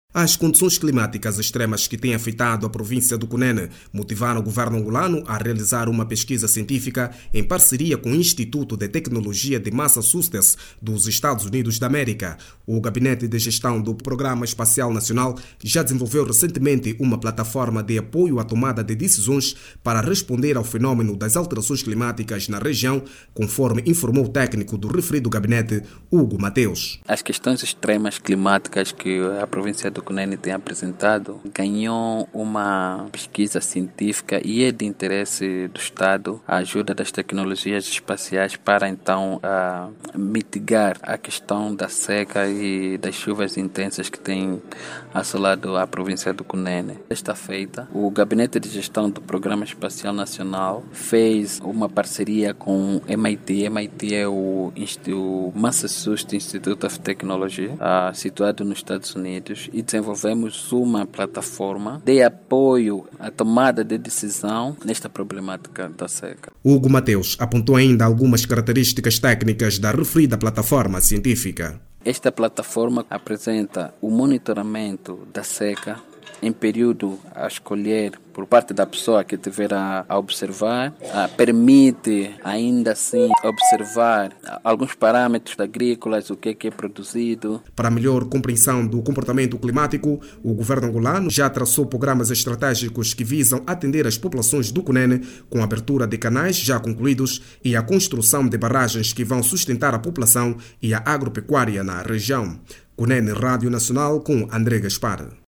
O governo angolano criou uma parceria com o Instituto de Tecnologia de Massachusetts dos EUA para a realização de uma pesquisa científica sobre as alterações climáticas no Cunene. O estudo vai permitir controlar as condições climáticas extremas que afectam o Cunene. Clique no áudio abaixo e ouça a reportagem